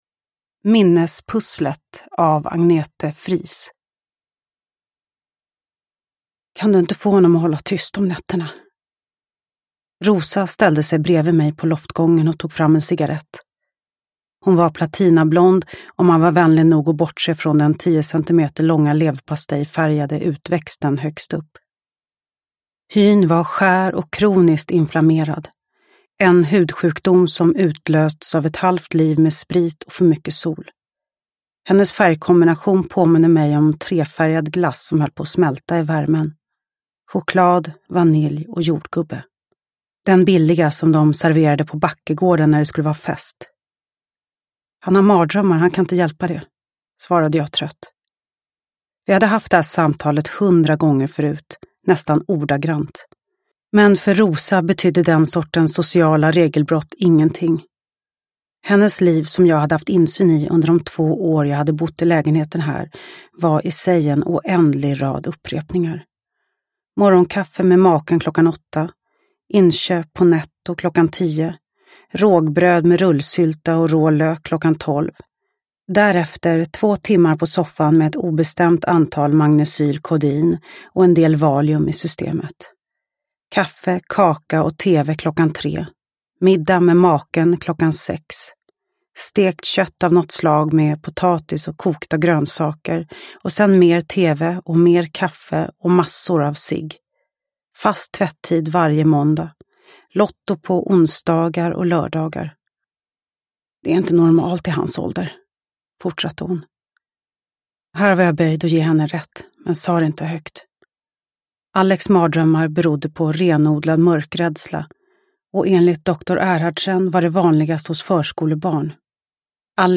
Minnespusslet – Ljudbok – Laddas ner
Uppläsare: Lo Kauppi